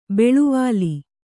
♪ beḷuvāli